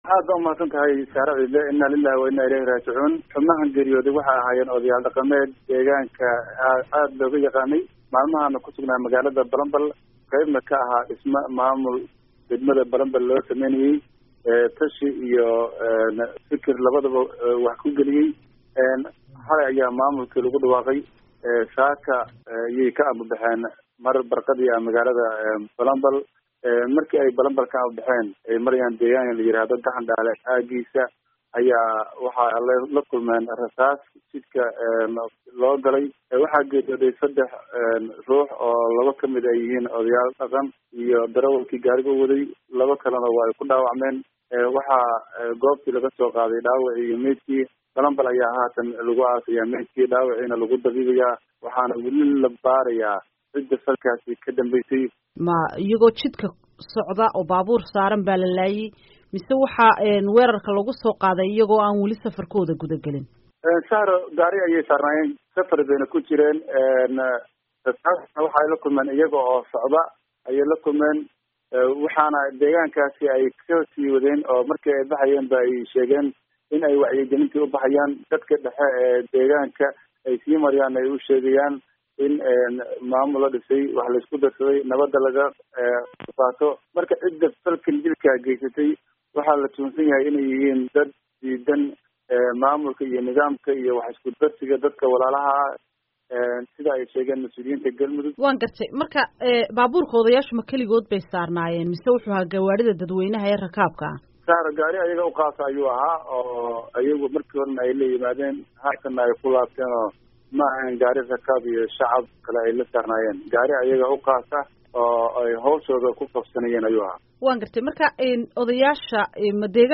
Wareysi